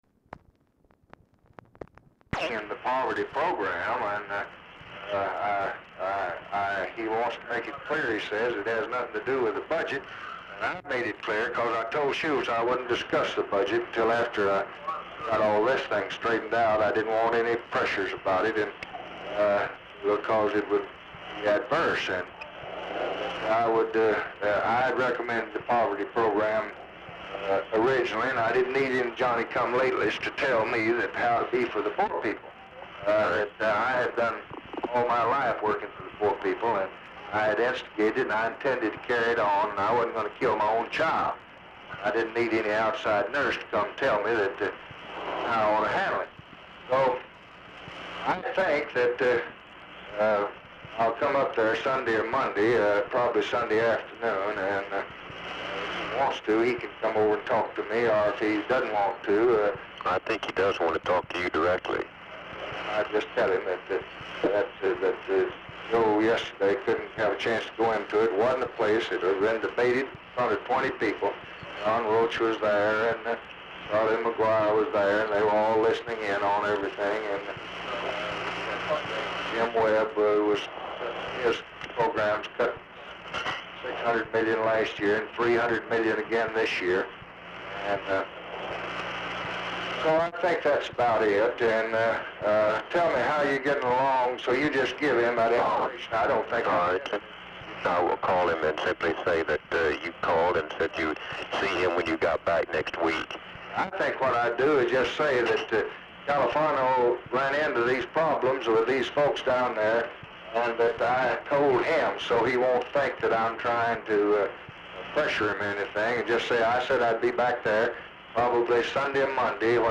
Telephone conversation
CONTINUES FROM PREVIOUS RECORDING; POOR SOUND QUALITY
Format Dictation belt
LBJ Ranch, near Stonewall, Texas